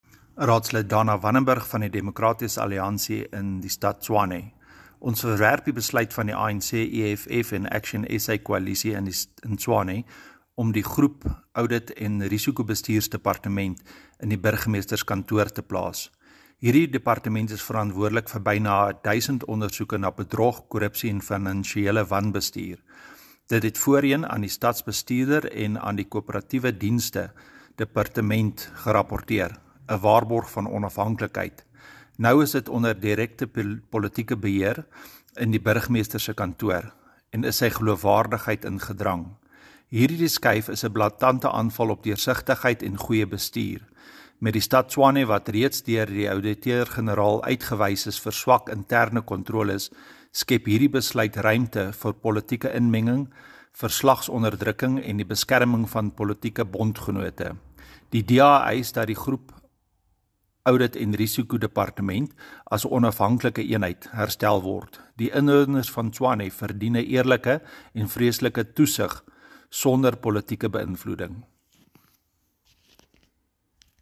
Note to Editors: Please find soundbites in English and Afrikaans by Ald Dana Wannenburg